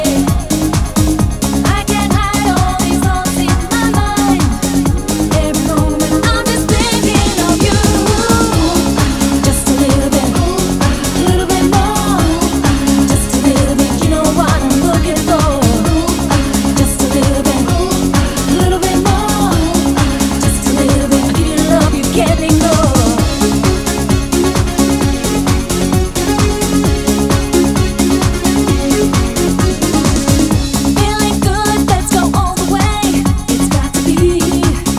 Жанр: Поп музыка